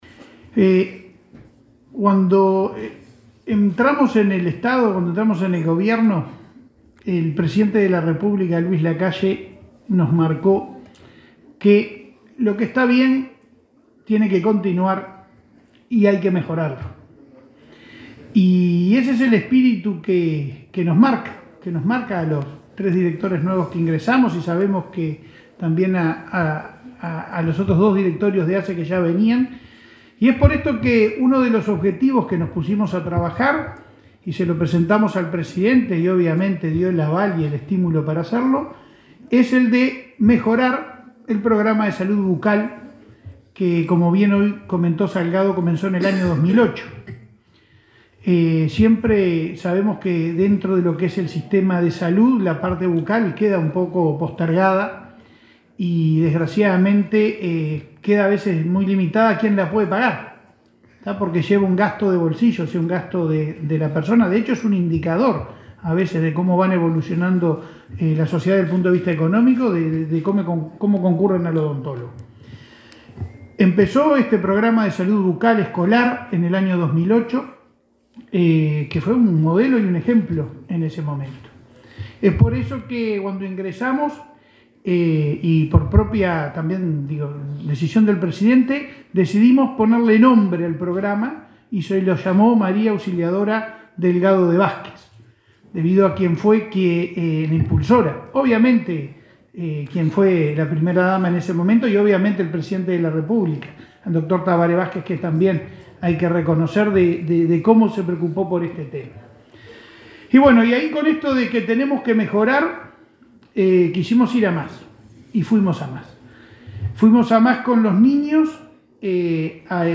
Palabras del presidente de ASSE, Leonardo Cipriani